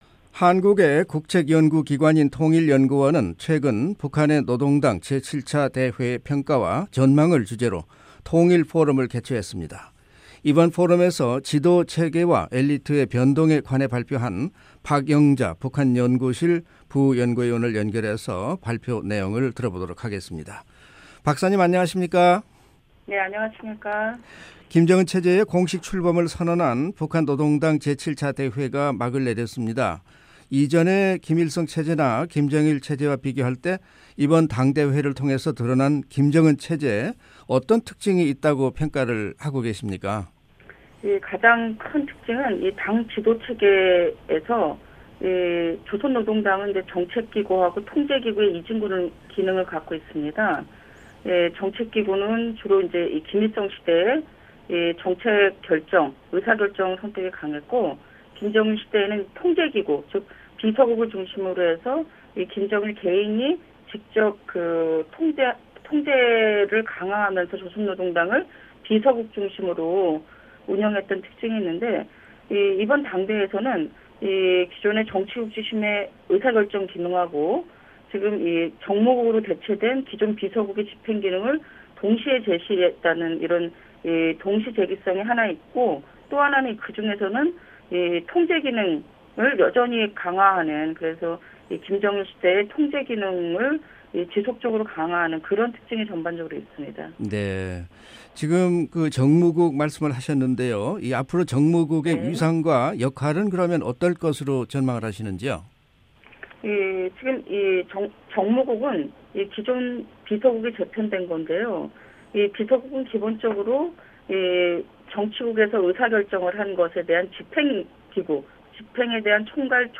[인터뷰 오디오]